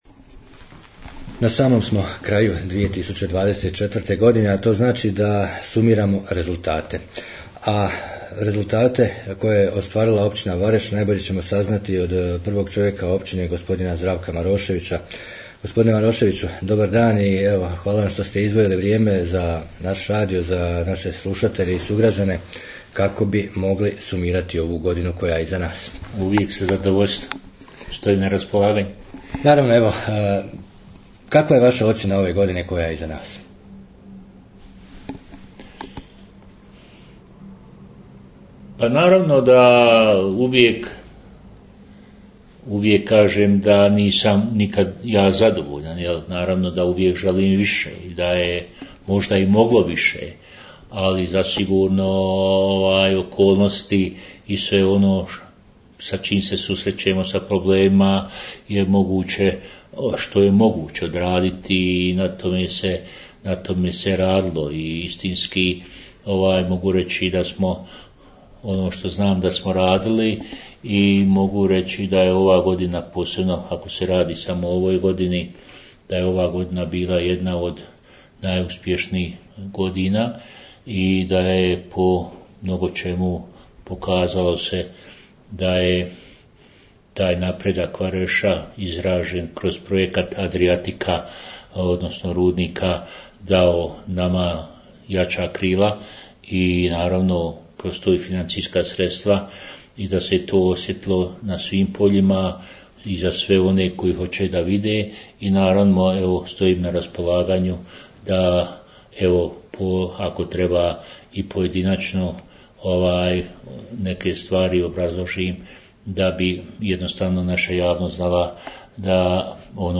Razgovor s načelnikom Zdravkom Maroševićem o 2024. godini